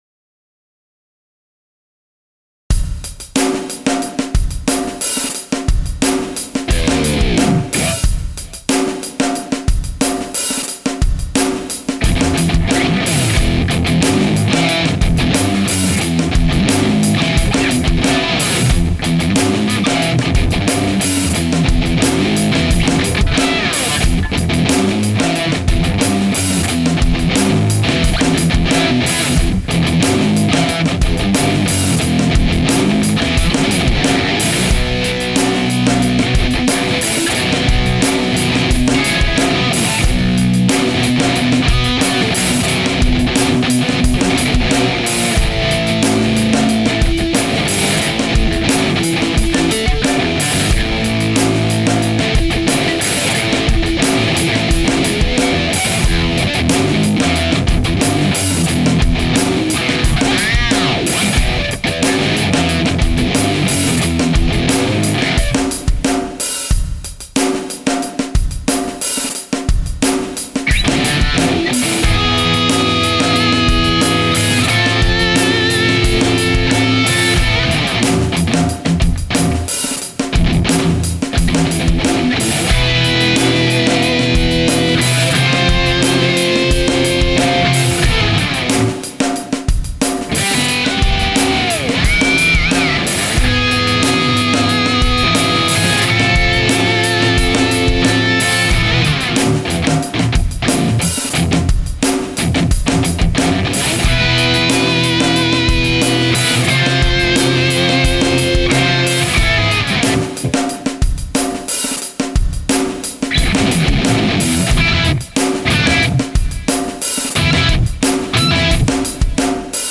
I threw this short diddy together to demo the Octaver in Guitar Rig 5
It's a really cool effect and you can set it up a ton of different ways
Just a drum loop and my guitar
The guitar gets such a deep throaty tone with the Octaver